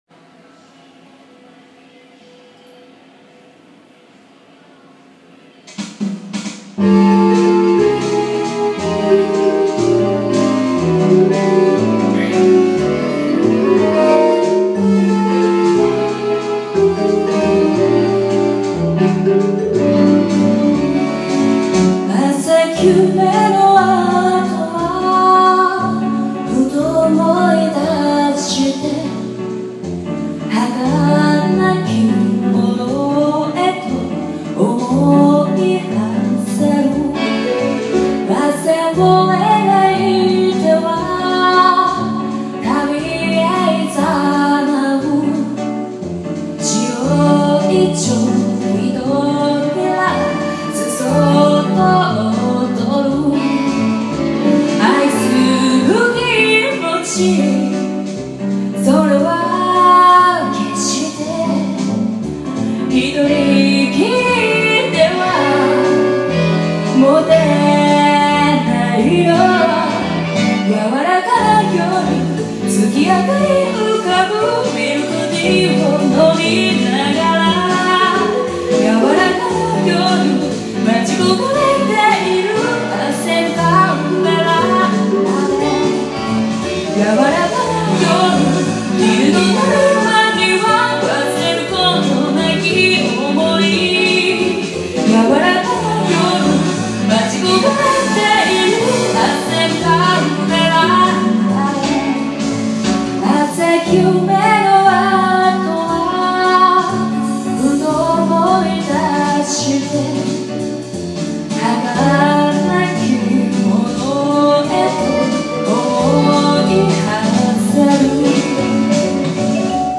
せっかくなので、はやりの「歌ってみた」↓
あいぽんのボイスメモ、便利だね♪